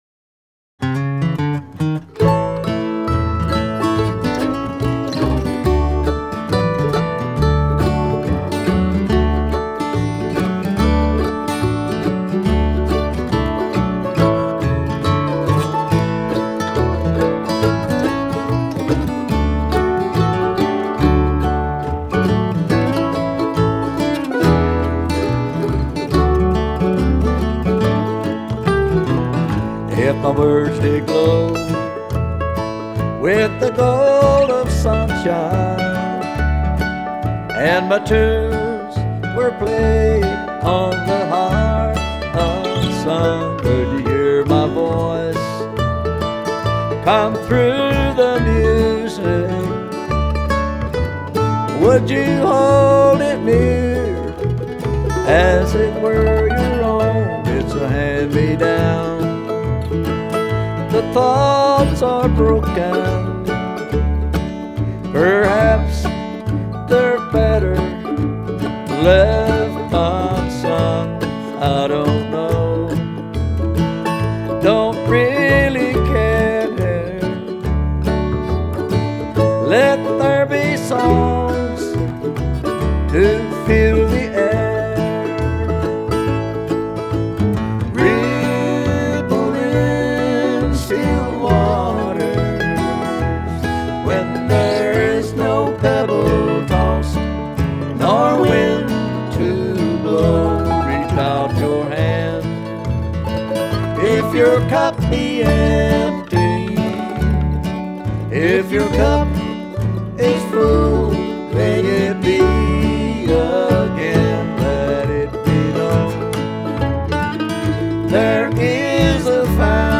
lead vocals
mandolin